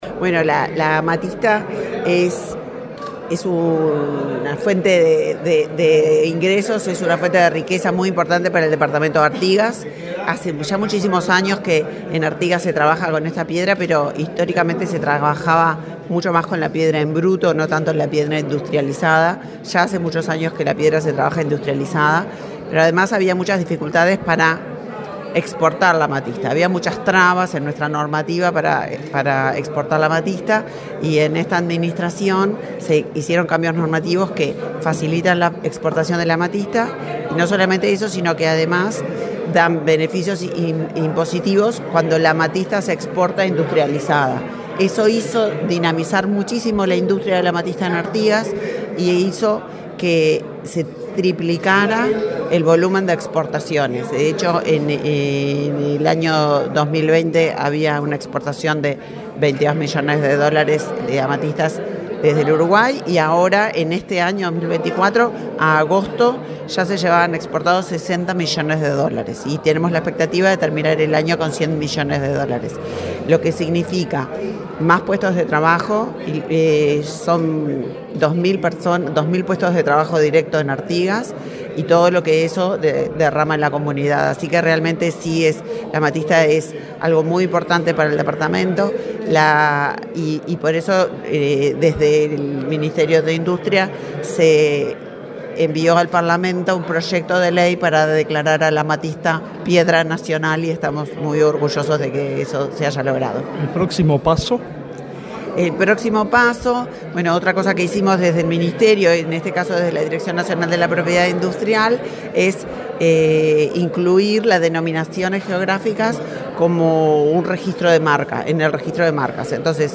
Declaraciones de la ministra de Industria, Elisa Facio
Declaraciones de la ministra de Industria, Elisa Facio 16/10/2024 Compartir Facebook X Copiar enlace WhatsApp LinkedIn Este miércoles 16 en Montevideo, la ministra de Industria, Elisa Facio, dialogó con la prensa, luego de participar en la ceremonia de designación de la amatista como piedra nacional.